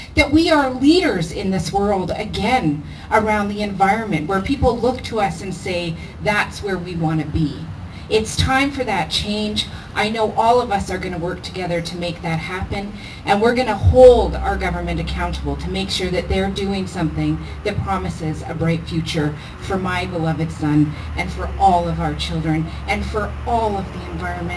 Campbell Riverites gathered yesterday in Spirit Square for the People’s Climate March.
New MP Rachel Blaney says if we don’t do anything about climate change, for every three breathes of air that we take today, there will only be oxygen for us to take two in the future.